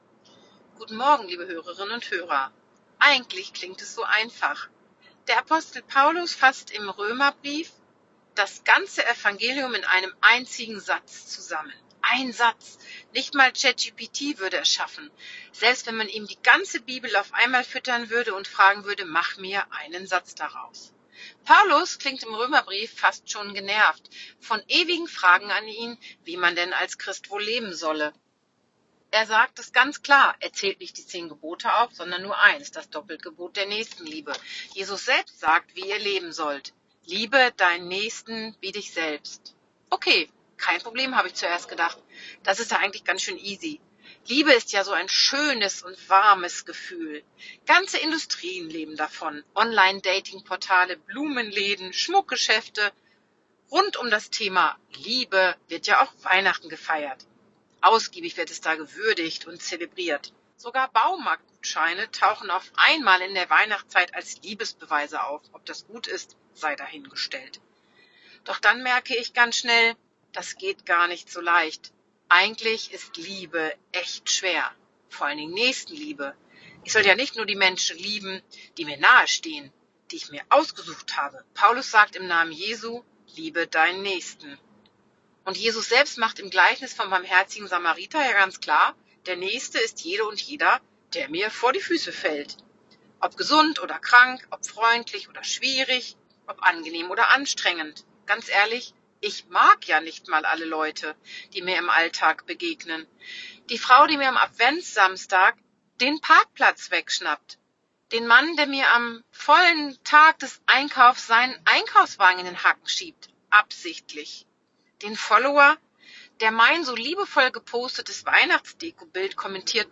Radioandacht vom 5. Dezember